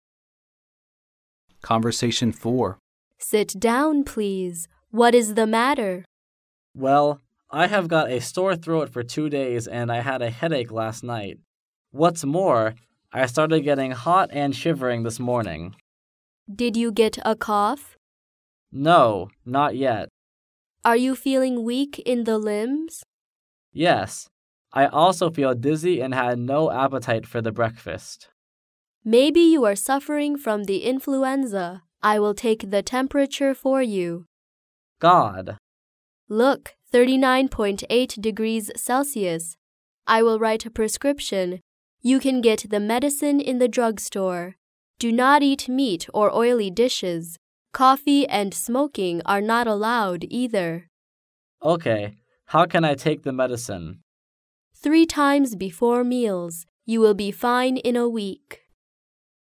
Conversation 4